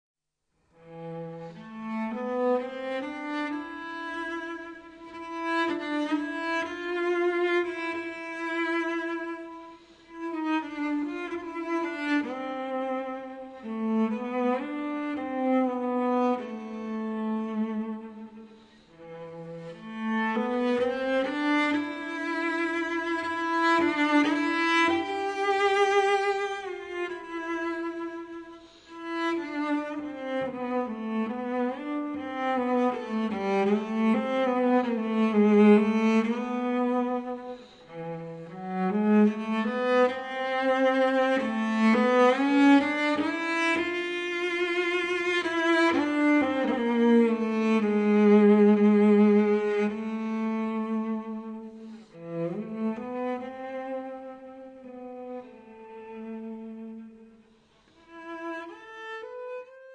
pianoforte
flicorno
contrabbasso
violoncello